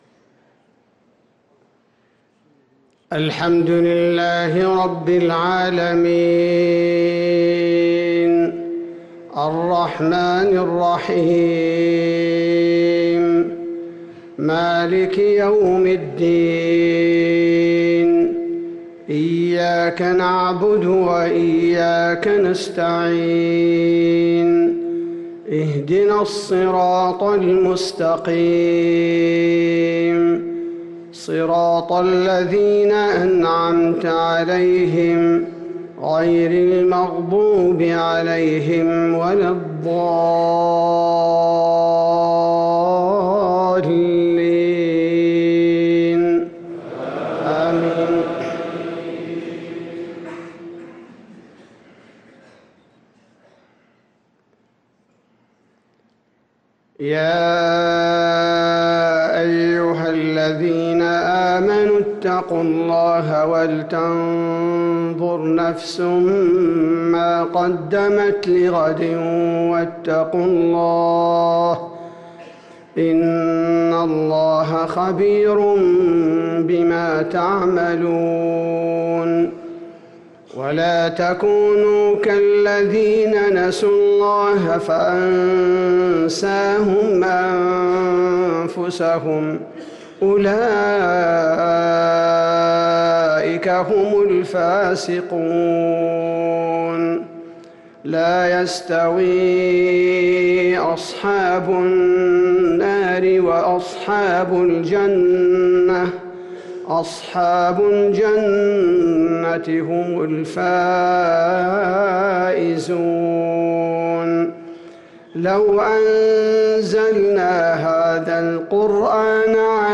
صلاة العشاء للقارئ عبدالباري الثبيتي 6 شوال 1444 هـ
تِلَاوَات الْحَرَمَيْن .